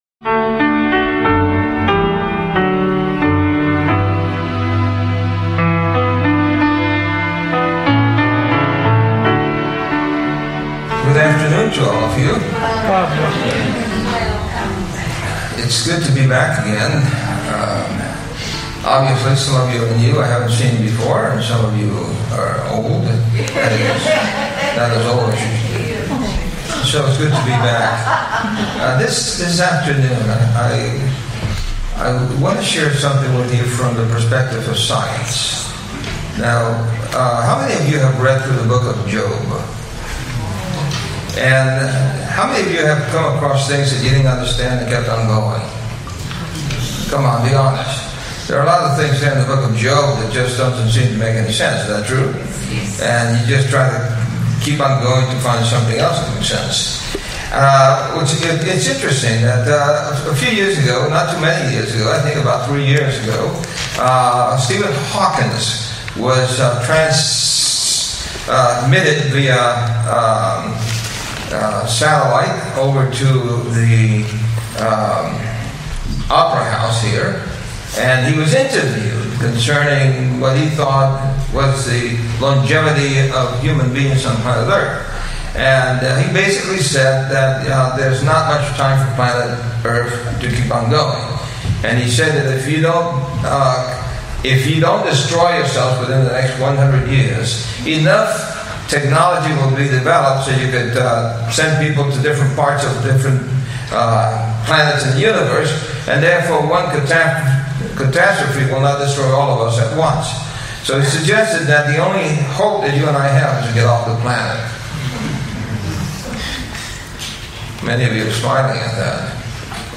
Are science and scripture at odds, or do they confirm each other? In this thought-provoking presentation, the speaker explores how scientific discoveries—like transparent gold and the motion of the Pleiades—align with biblical truths, revealing the divine wisdom embedded in scripture.